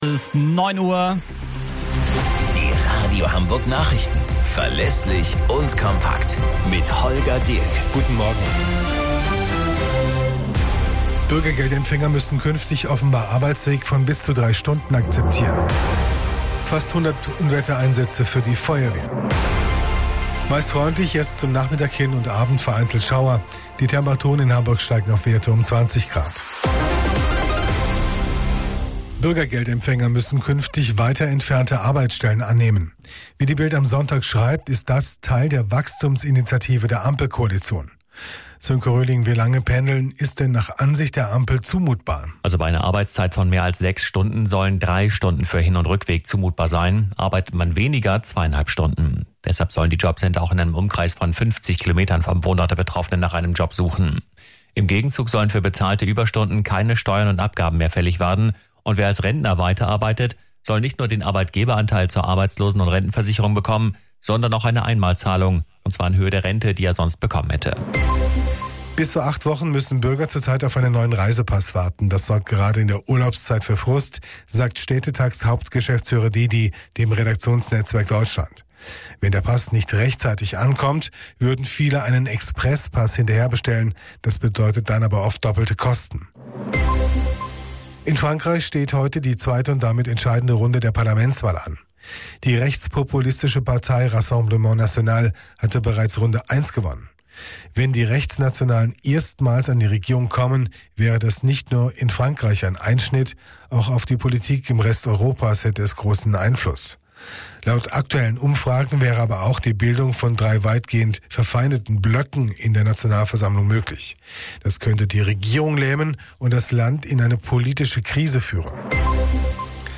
Radio Hamburg Nachrichten vom 07.07.2024 um 16 Uhr - 07.07.2024